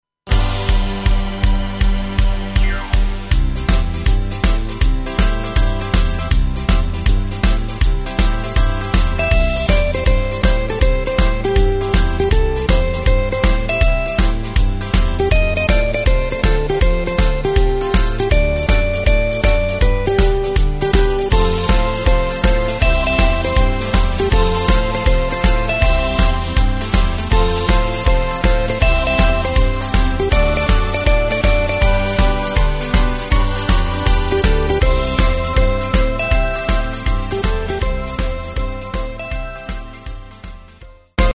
Takt:          4/4
Tempo:         160.00
Tonart:            C
Rock`n Roll aus dem Jahr 1979!